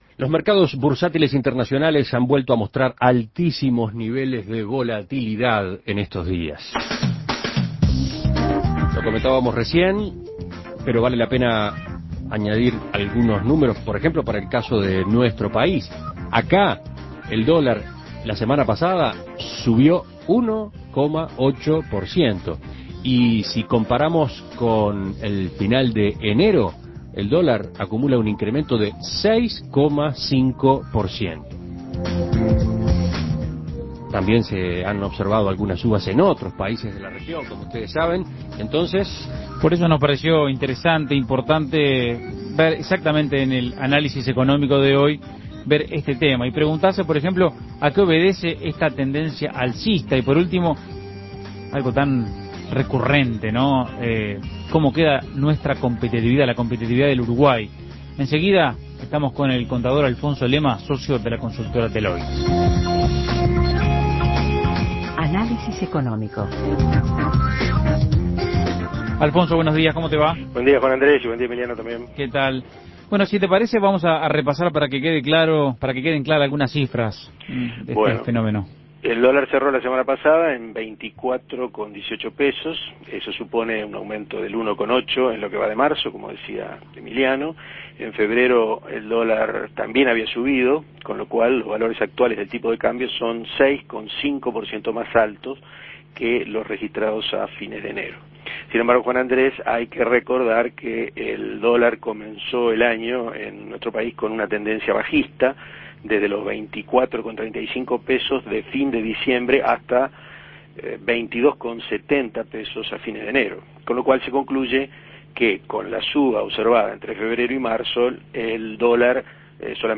Análisis Económico En Uruguay y en la región el dólar sube.